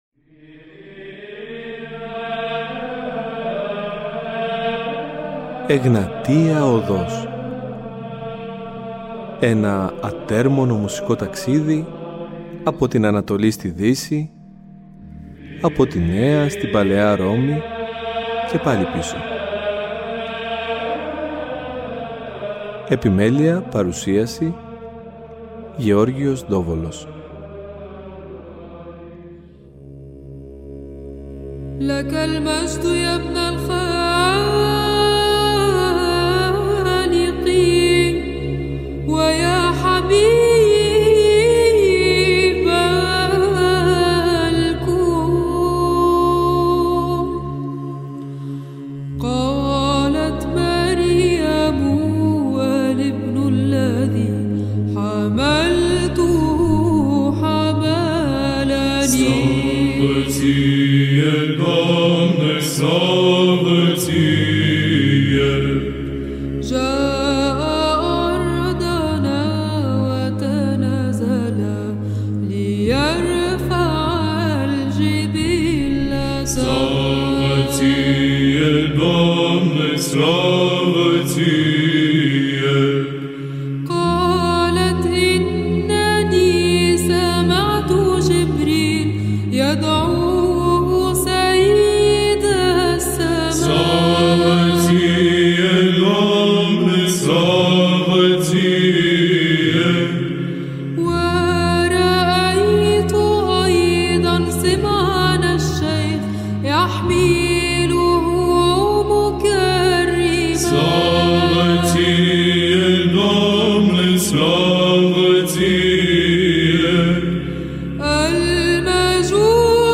Η γυναικεία φωνή στην ψαλτική τέχνη – Α’ Μέρος
Στις μέρες μας η γυναικεία ψαλτική συνεχώς καταλαμβάνει έδαφος στον ελλαδικό χώρο. Είτε λόγω λειψανδρίας, είτε λόγω αυτής καθαυτής της ιδιαίτερης φωνητικής χροιάς που μέσα στους ναούς ηχεί ξεχωριστά.